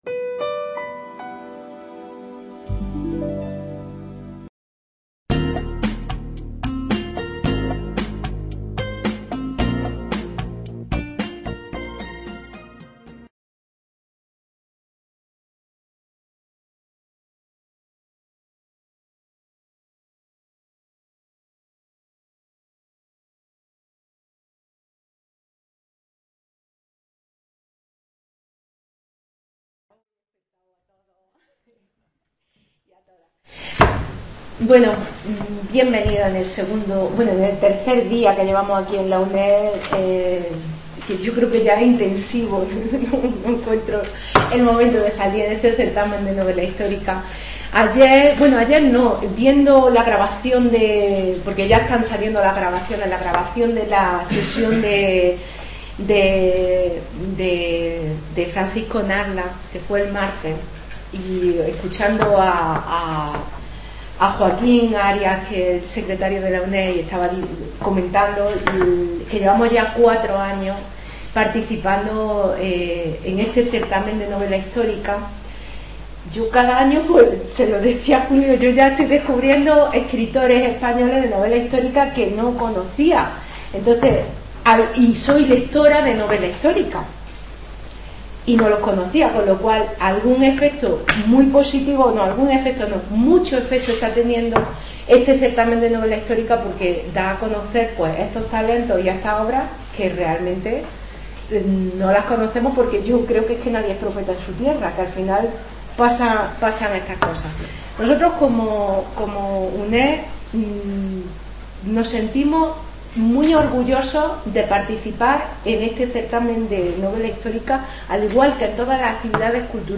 Las islas de poniente - Certamen Internacional Novela Histórica de Úbeda (2019)